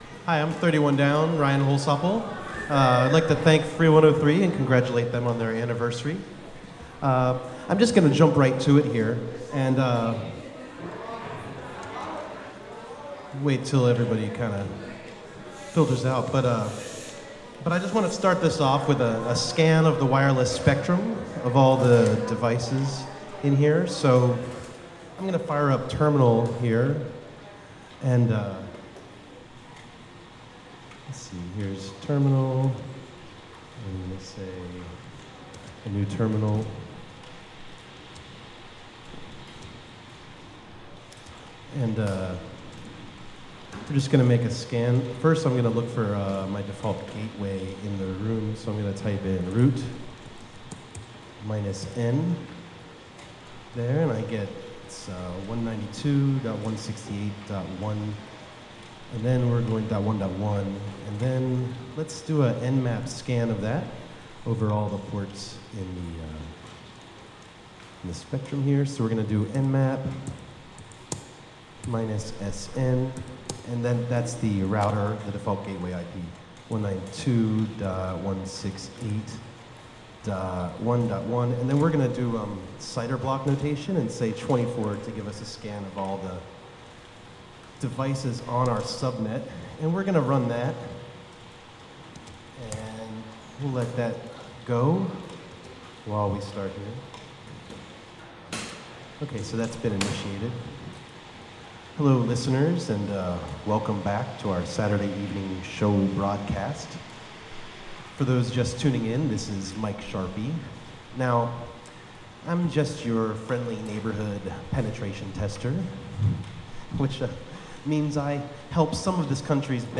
31 Down performance during Wave Farm 1997-2017 at Fridman Gallery, NYC.
This durational celebration features live performa...